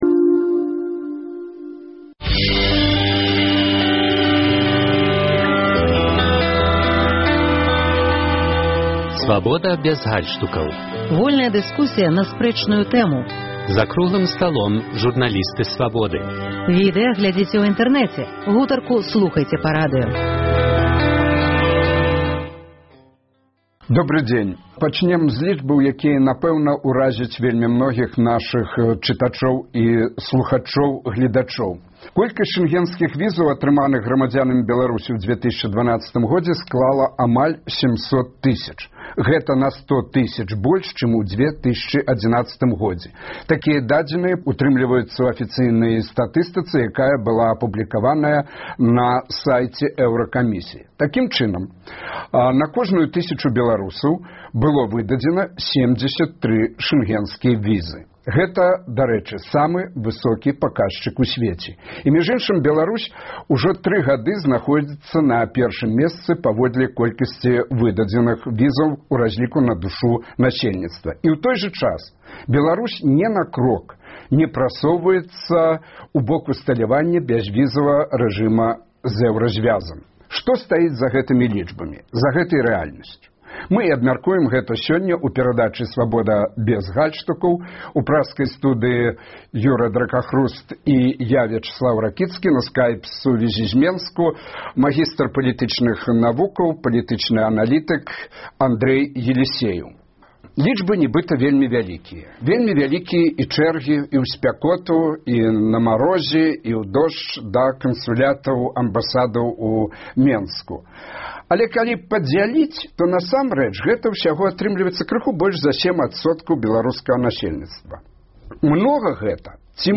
Абмеркаваньне гарачых тэмаў у студыі Свабоды. Ці лёгка беларусы атрымліваюць шэнгенскія візы?